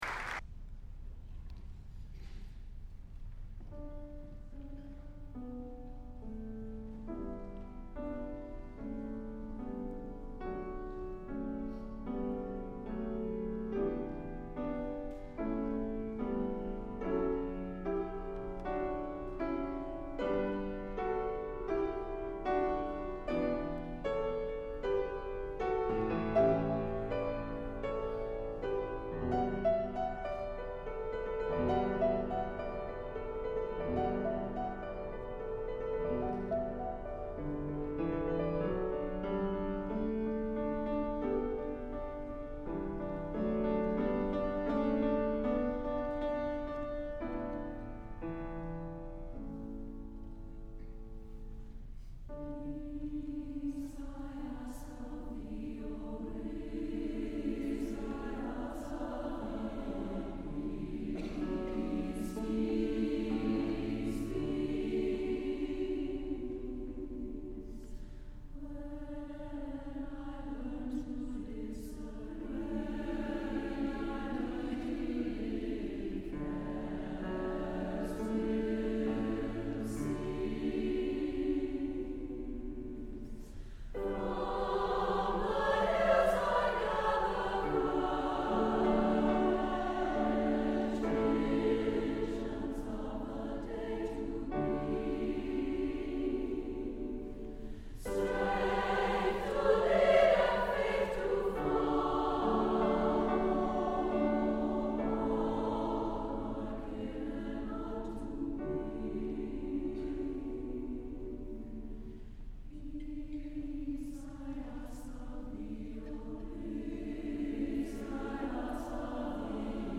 for SSAA Chorus and Piano (2005)
with piano
The work opens with an ascending scale, as a supplication.